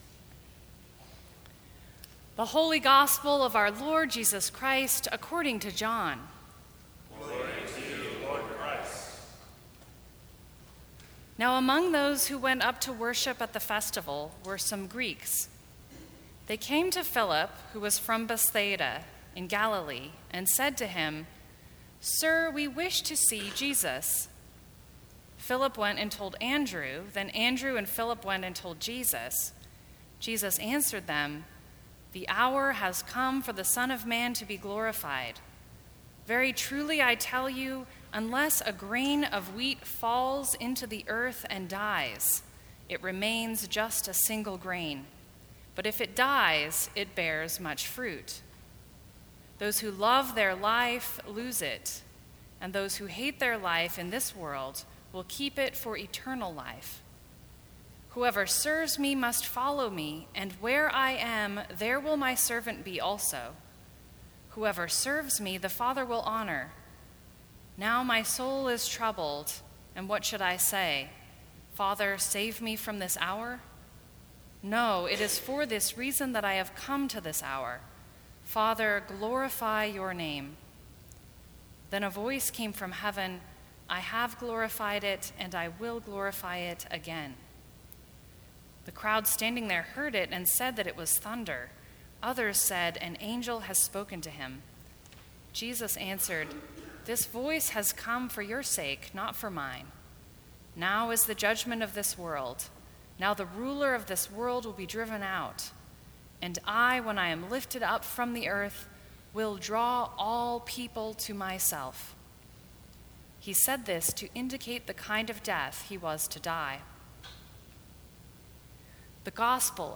Sermons from St. Cross Episcopal Church Seeds of Change Mar 18 2018 | 00:18:42 1x 00:00 / 00:18:42 Subscribe Share Apple Podcasts Spotify Overcast RSS Feed Share Link Embed